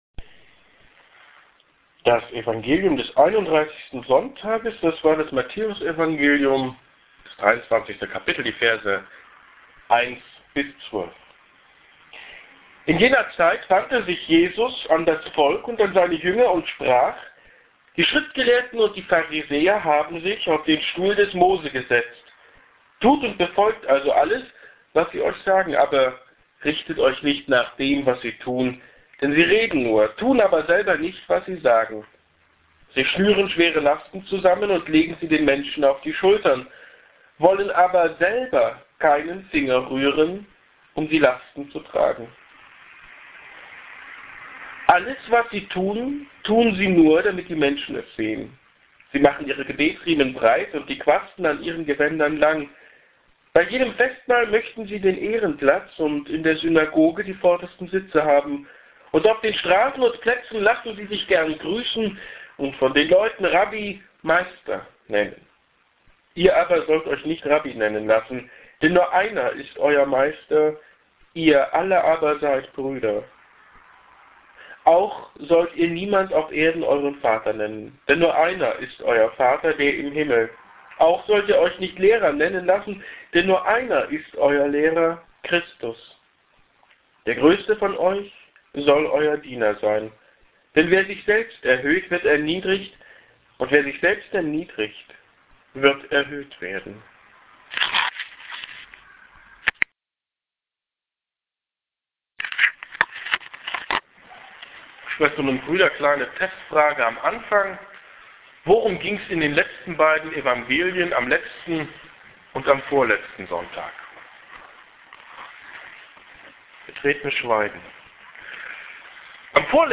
Predigten im Jareskreis
hier-klickt-die-predigt.mp3